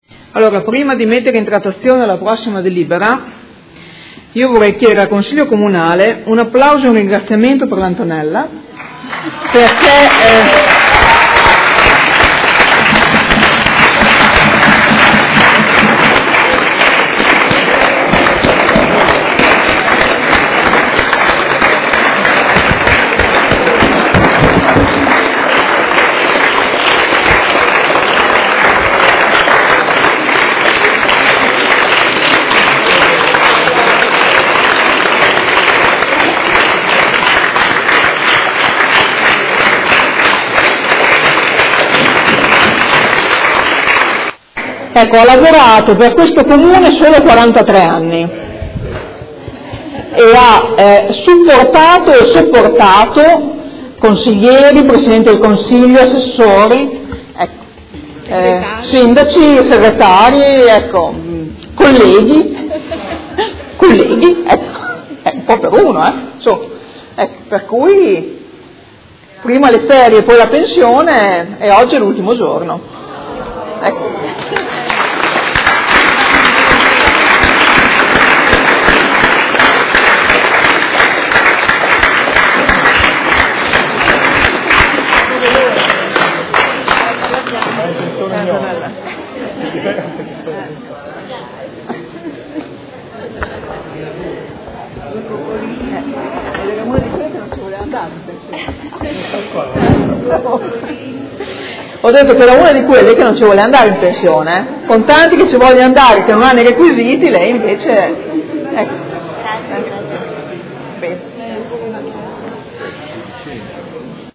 Seduta del 04/06/2018. Comunicazione su pensionamento dipendente comunale